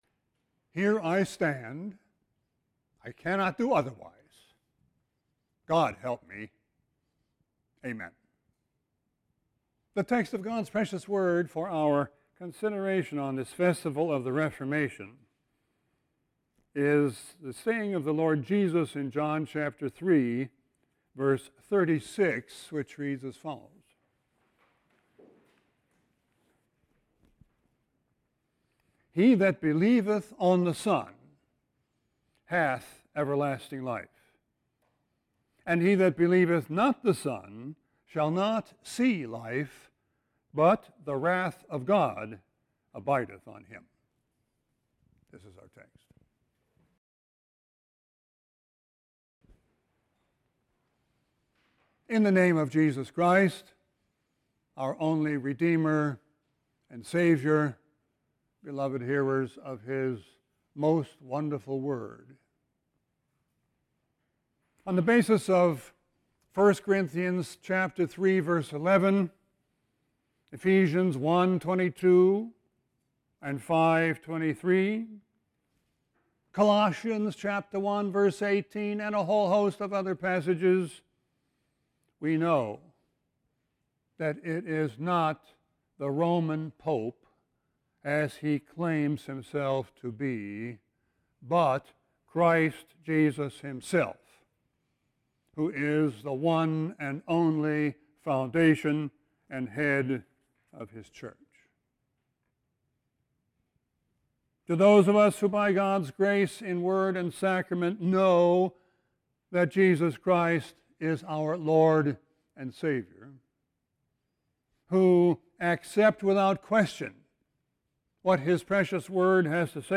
Sermon 11-3-19.mp3